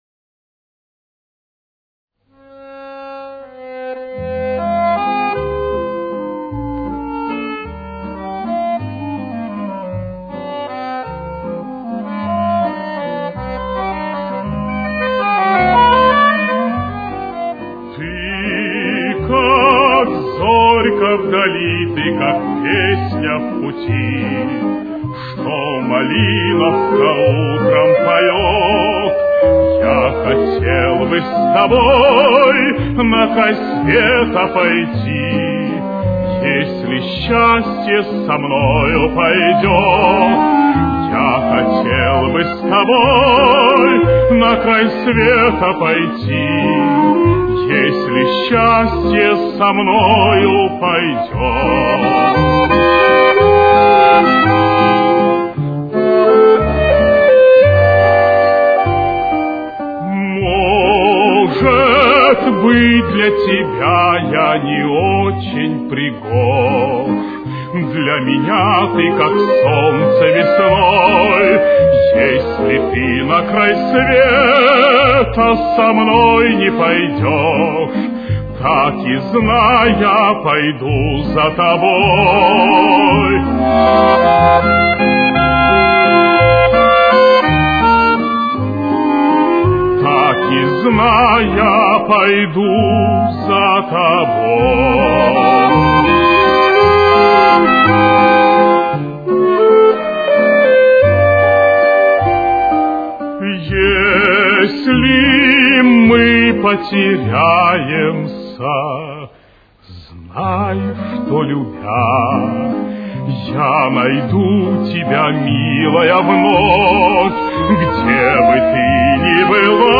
До минор. Темп: 163.